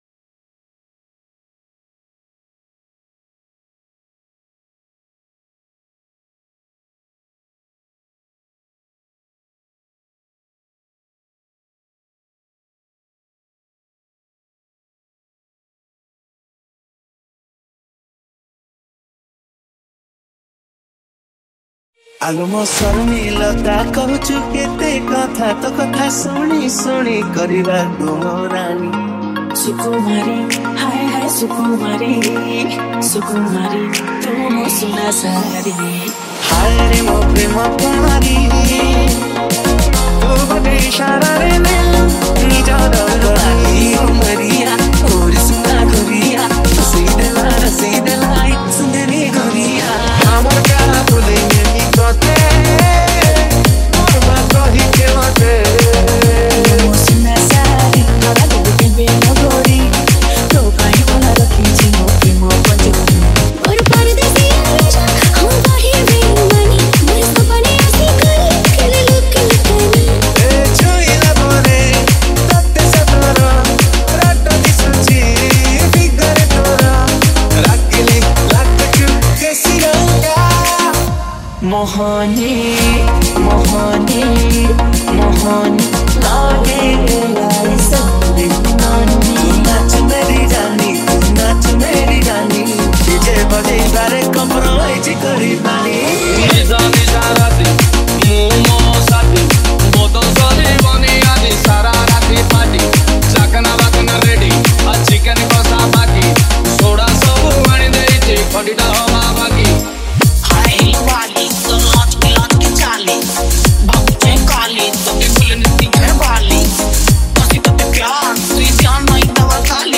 Category : MASHUP 2022